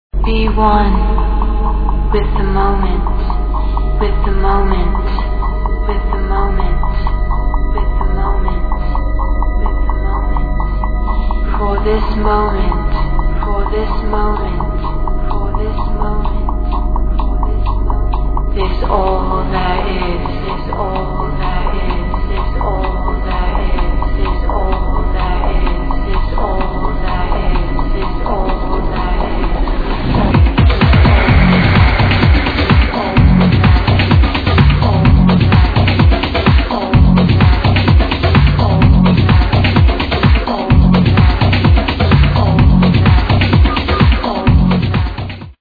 Supreme tranceaddict